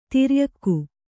♪ tiryakku